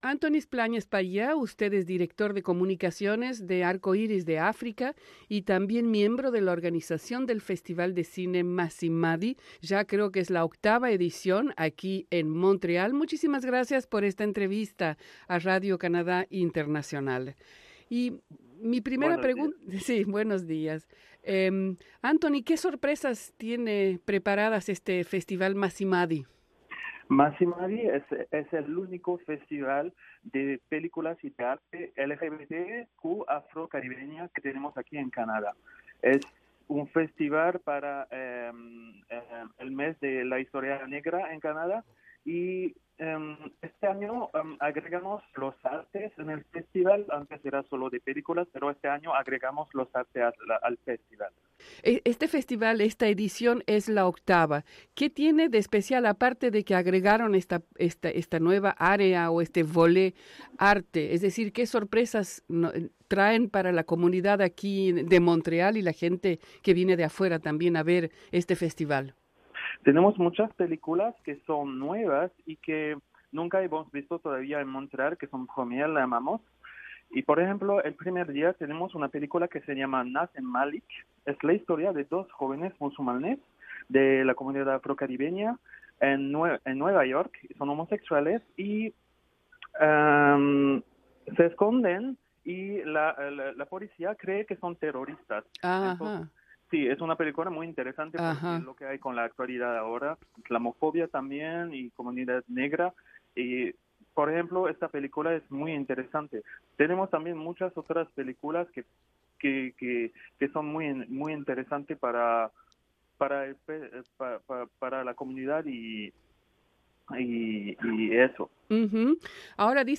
Esto y más en la entrevista.